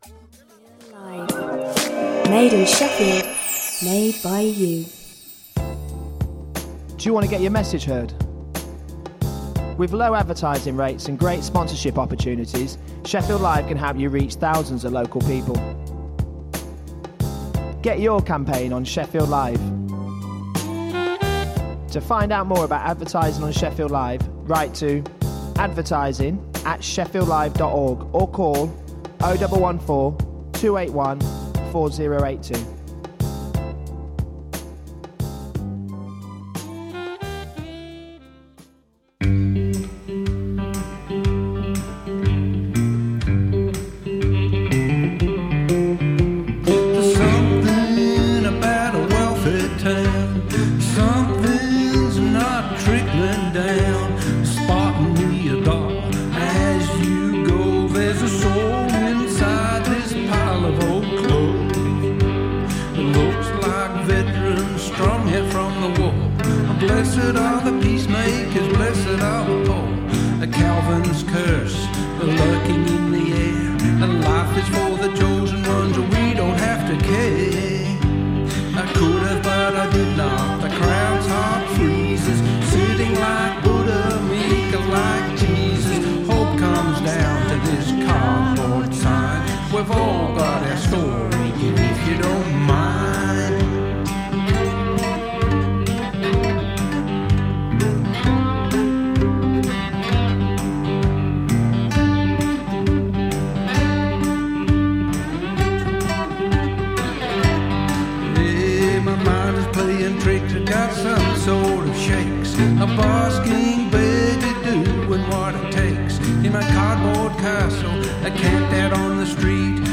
Shefffield Live presents…Aaj Ka Sabrang : A mix of different flavours of Asian music from the sub-continent and chat.